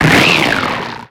Cri d'Opermine